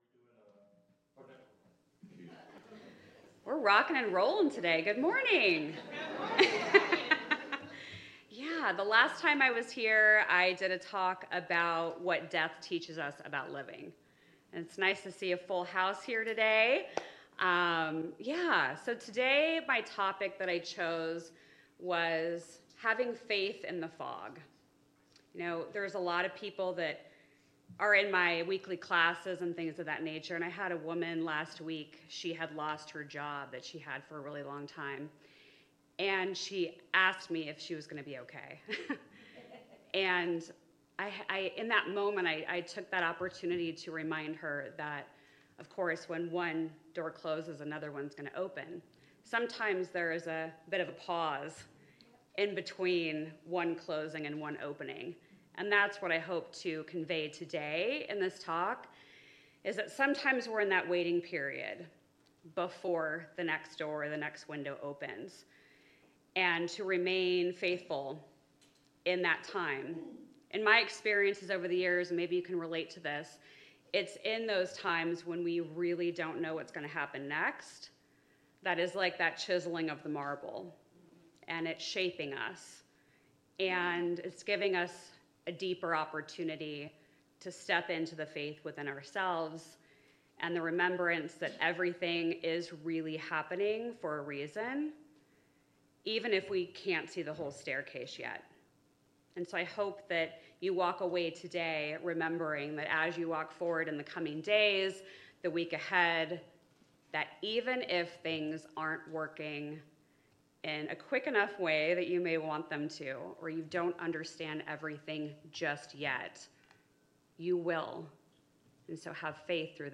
The audio recording (below the video clip) is an abbreviation of the service. It includes the Message, Meditation, and Featured Song.